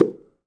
Block Soccerball Dispense Sound Effect
block-soccerball-dispense.mp3